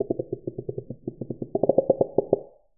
huskclicking3.ogg